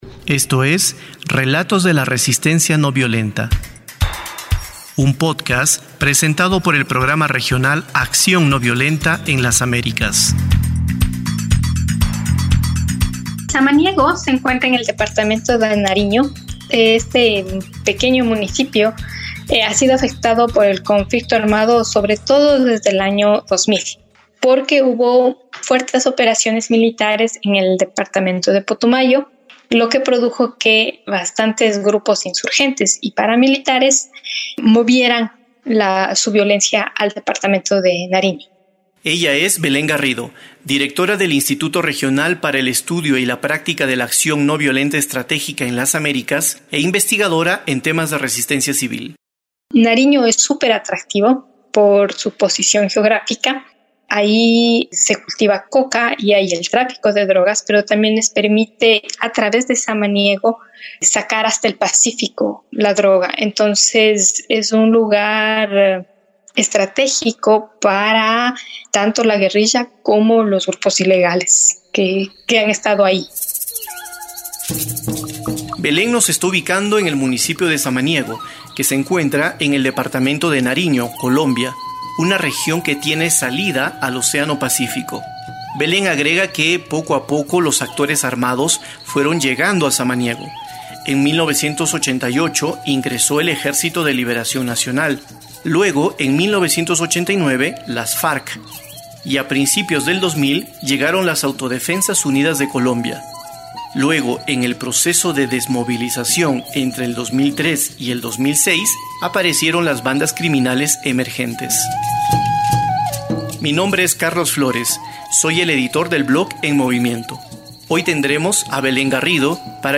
Tamaño: 13.77Mb Formato: Basic Audio Descripción: Entrevista - Acción ...